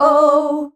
OUUH  D.wav